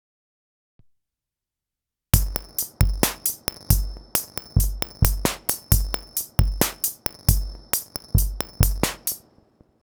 そこで、その元ネタになっているAASのCHROMAPHONE 3をセール期間中に購入し、何か適当なプリセットを探しつつ音を弄っているうちに、今回の楽曲のイントロはチープなリズムマシンの音から始めたいと考え始め、Roland CR-78をイメージして「CK-78」というバスドラ＆スネアの音色を用意し（CKはCheap Kitの略）、そこへパーカッション系を追加してパターンを組んでみた。
Intro-CK78.m4a